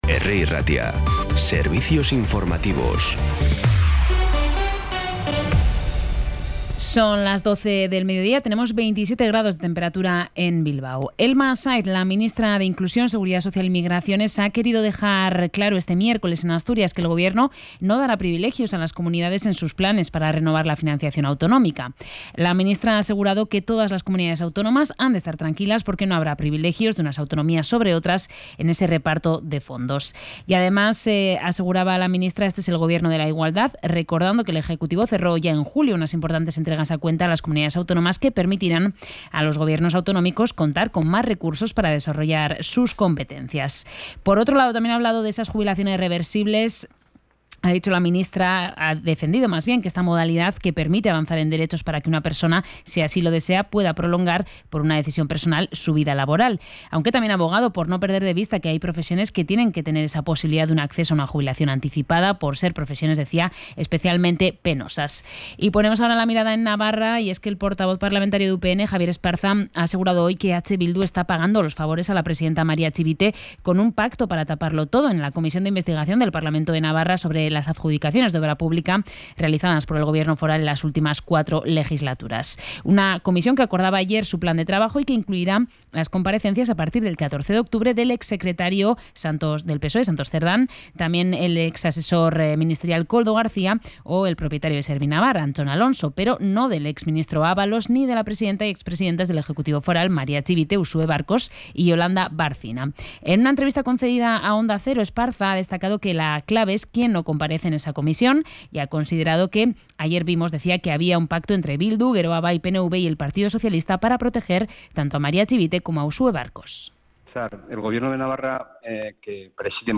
Información y actualidad desde las 12 h de la mañana